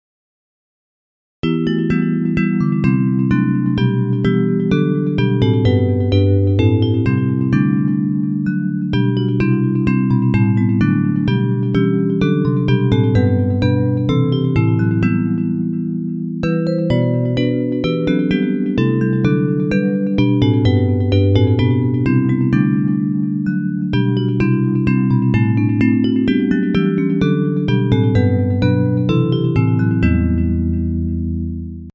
Hymn lyrics and .mp3 Download
Bells Version
Music by: English melody;